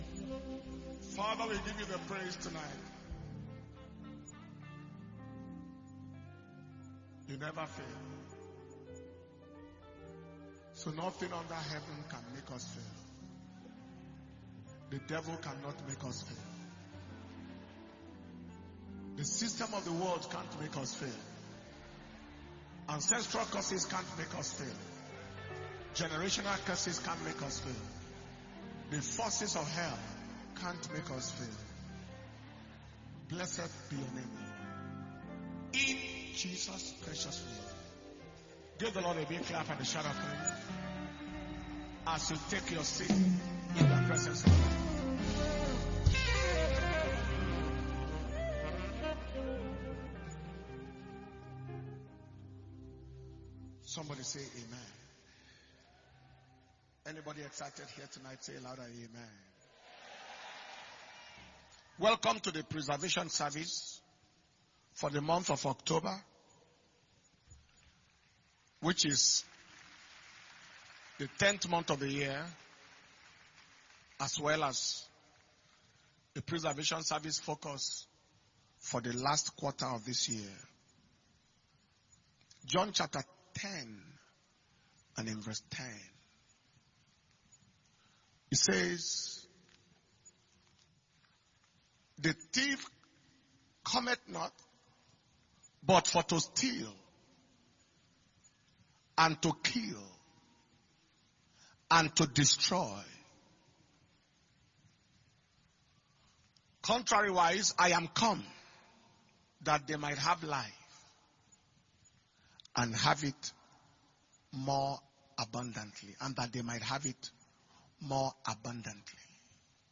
October 2022 Preservation And Power Communion Service – Wednesday, 5th October 2022